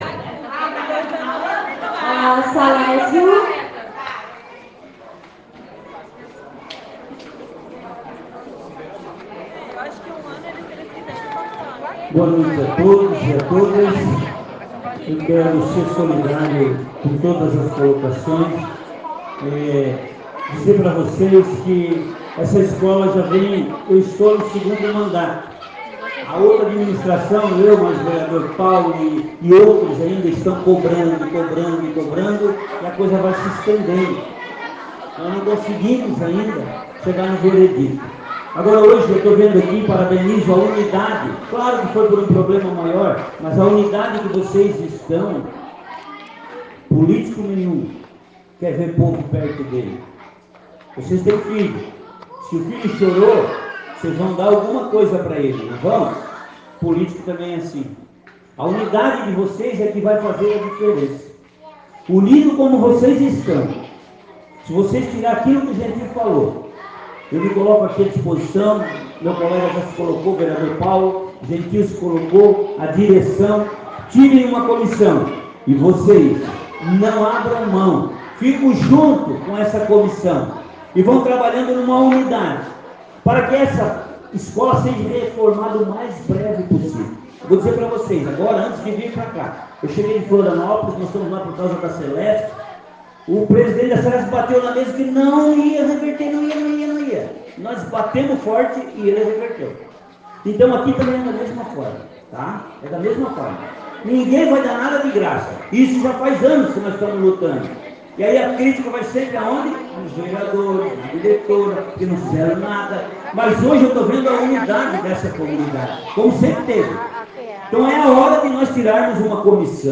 O vereador Salésio Lima (PSD), esteve na reunião realizada na terça-feira (19), discutindo com os pais e lideranças a situação da estrutura da Escola Amaro João Batista, no Bairro Nova Esperança e uma frase chamou a atenção de uma mãe que gravou o discurso do parlamentar.
Discurso do vereador Salésio Lima na íntegra